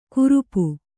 ♪ kurupu